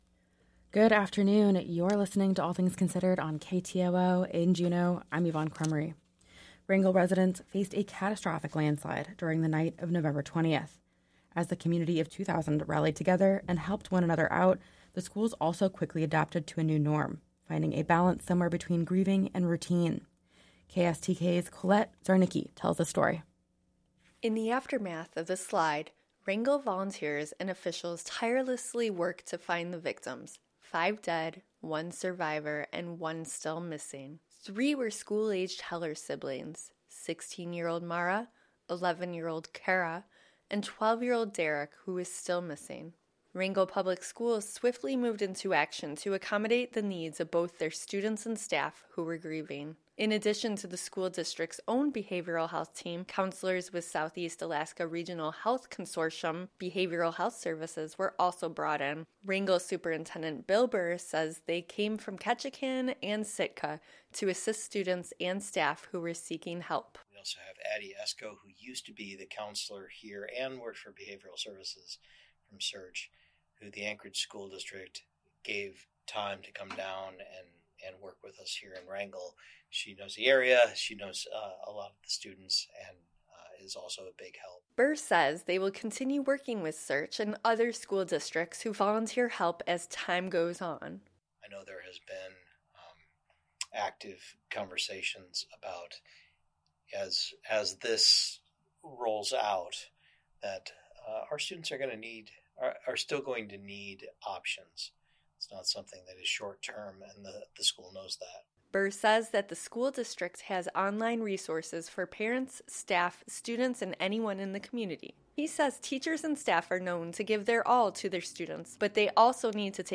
Newscast – Thursday, December. 7 2023